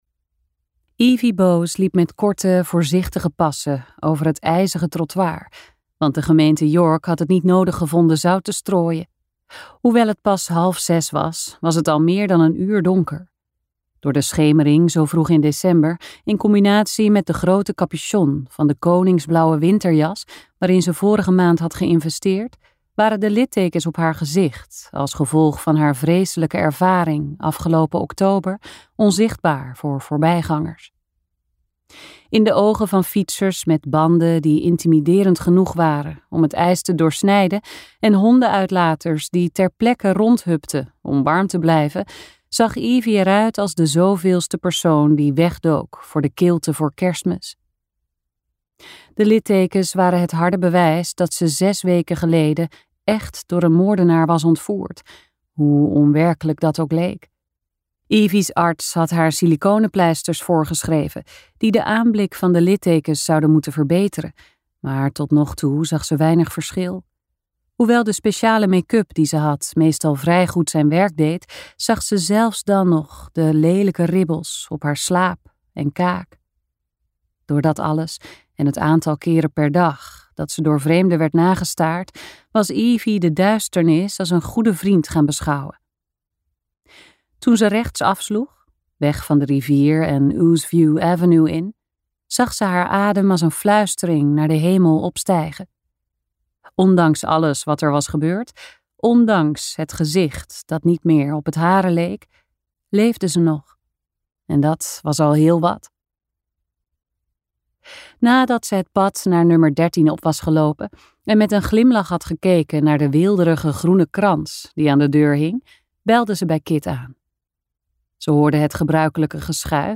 Uitgeverij De Fontein | Een mysterie in de boekhandel luisterboek